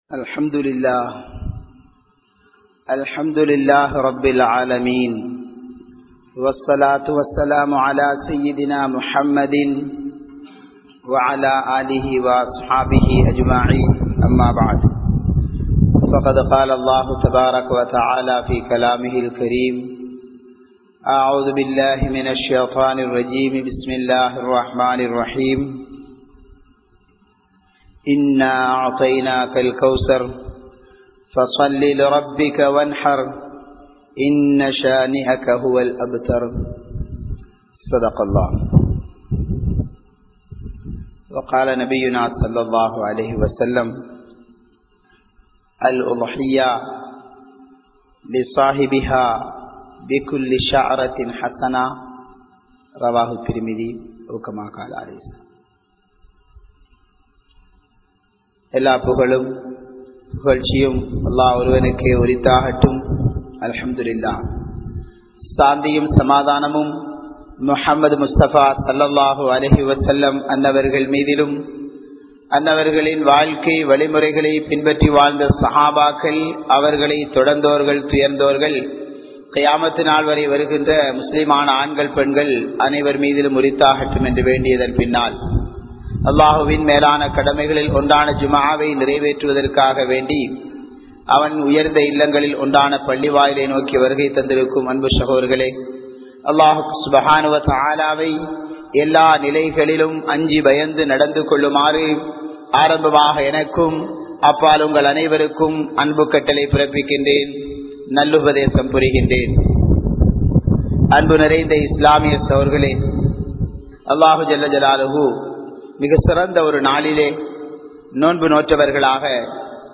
Oru Kudumbaththin Thiyaaham (ஒரு குடும்பத்தின் தியாகம்) | Audio Bayans | All Ceylon Muslim Youth Community | Addalaichenai
Kanampittya Masjithun Noor Jumua Masjith